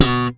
ALEM POPS B2.wav